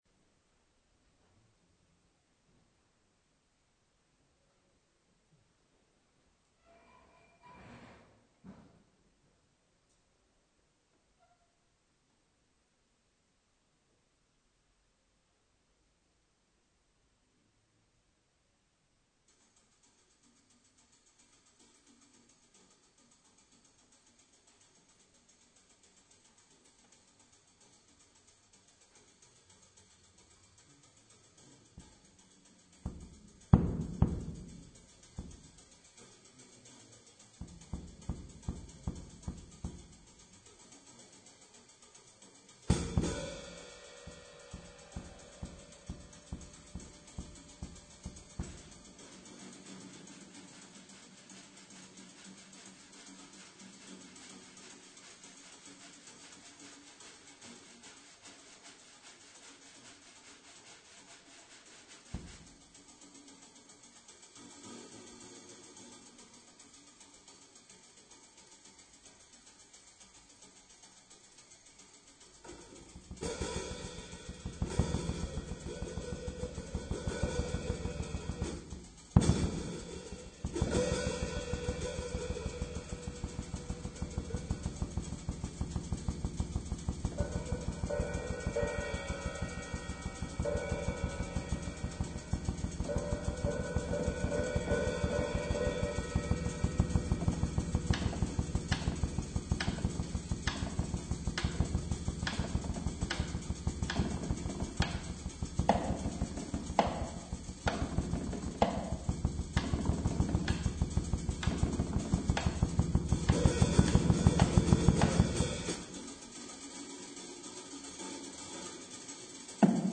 akustična tolkala in glas
elektronske zvočne intervencije